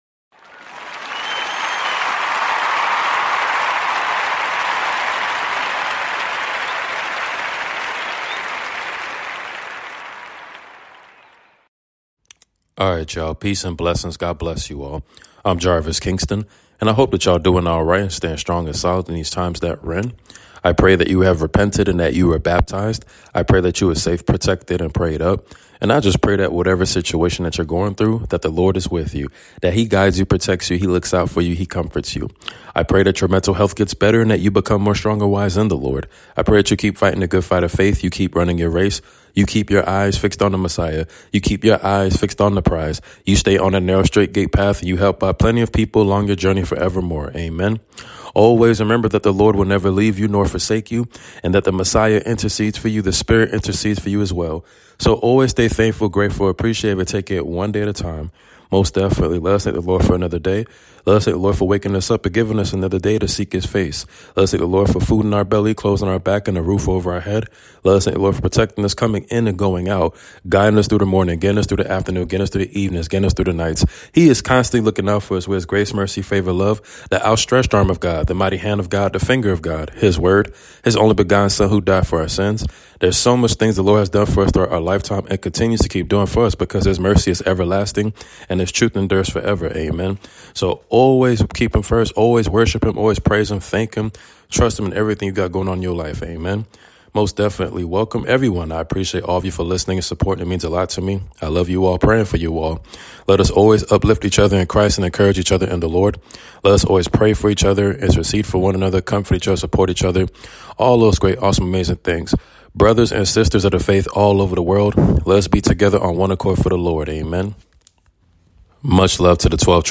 Another daily devotional based on The Messiah teaching us about self denial. We must put God first in our lives !